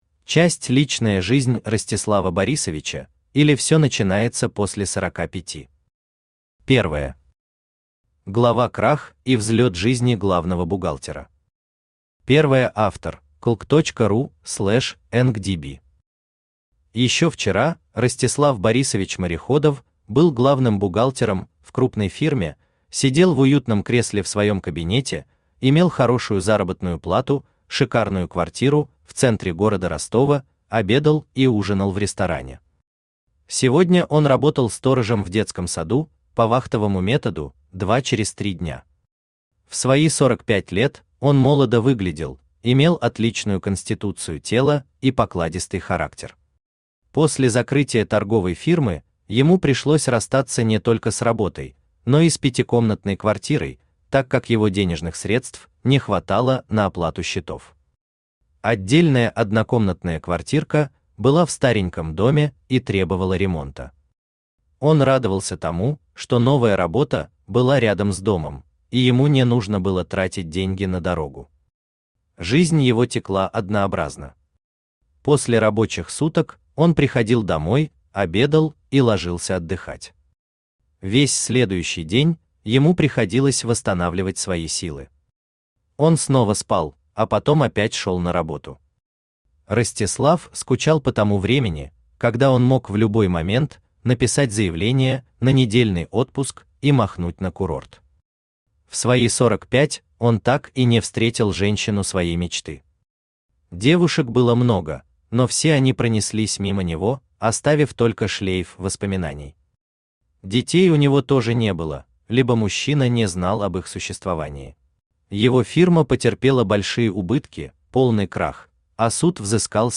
Аудиокнига Личная жизнь Ростислава Борисовича, или всё начинается после сорока пяти: Повесть в 2-х частях | Библиотека аудиокниг
Aудиокнига Личная жизнь Ростислава Борисовича, или всё начинается после сорока пяти: Повесть в 2-х частях Автор Юлиана Воронина Читает аудиокнигу Авточтец ЛитРес.